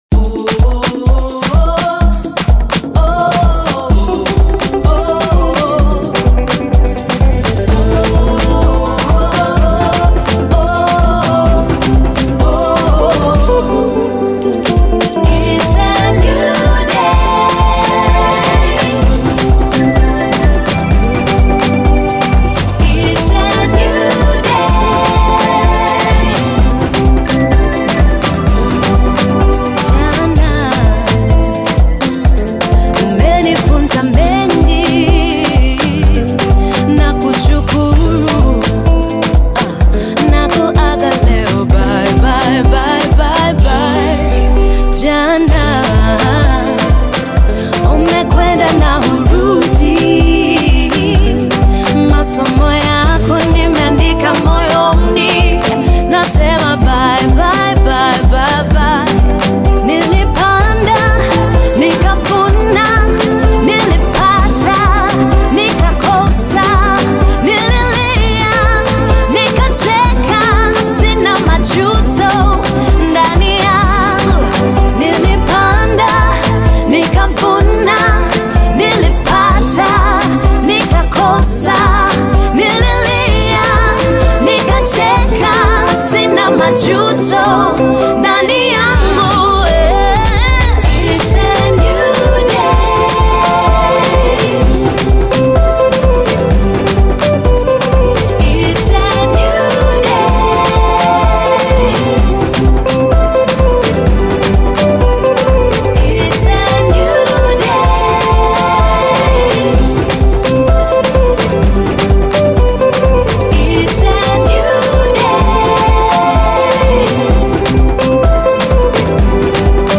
GOSPEL AUDIOS